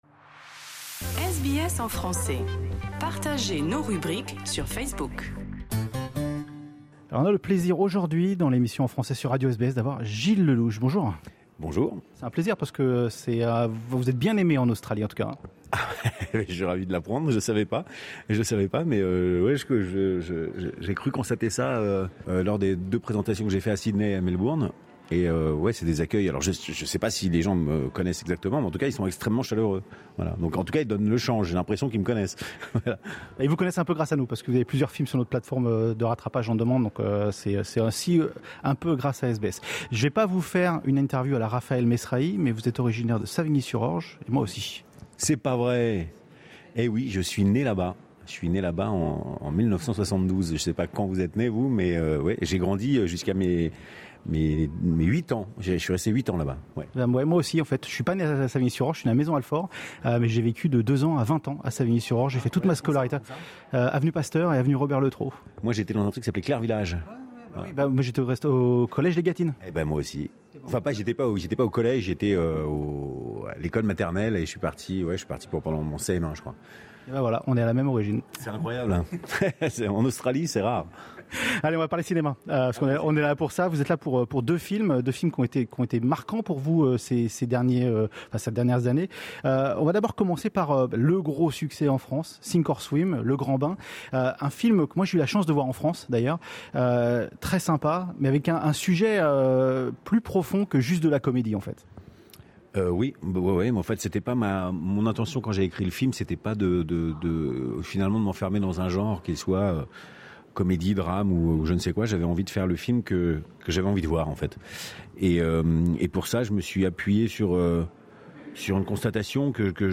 Gilles Lellouche est en Australie pour presenter deux films present au Festival du Film Francais de l'Alliance Francaise ... Une interview pleine de surprise...